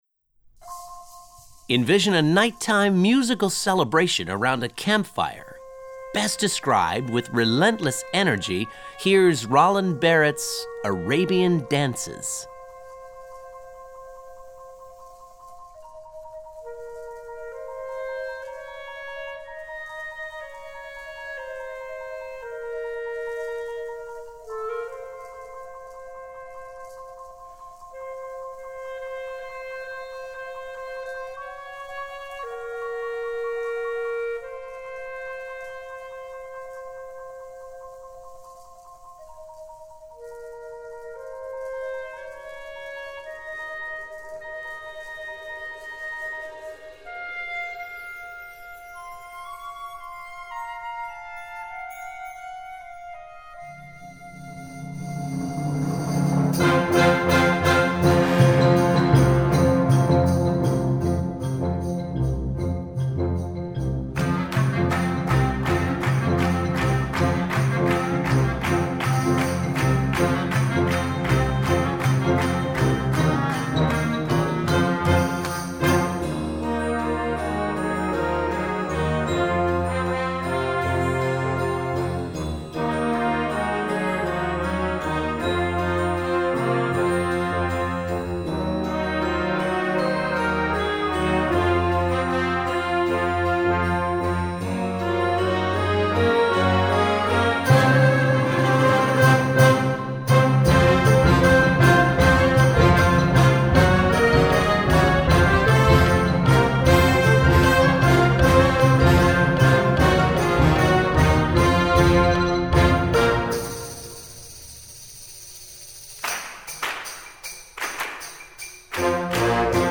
Voicing: Concert Band